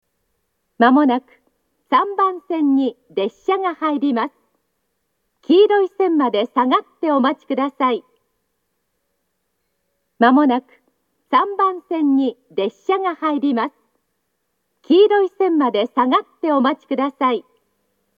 発車メロディーと接近放送があります。
３番線接近放送
aizu-wakamatsu-3bannsenn-sekkinn.mp3